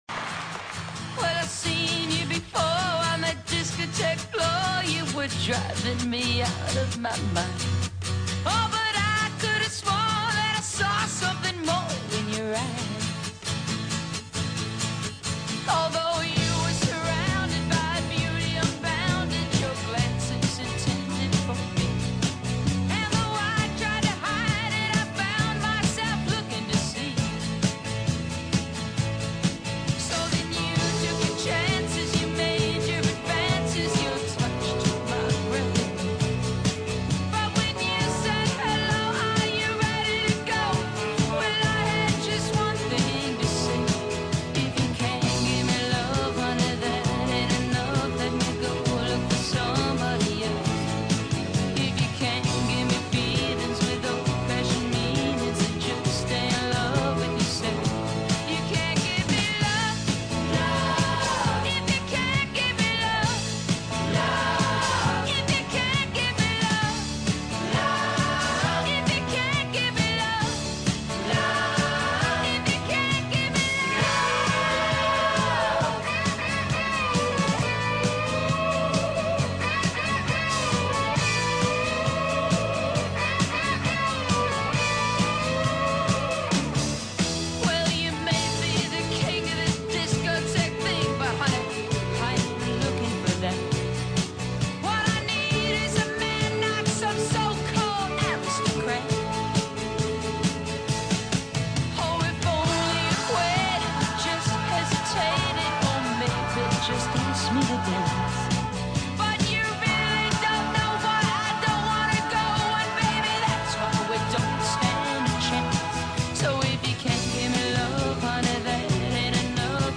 toca el bajo y fue una innovadora.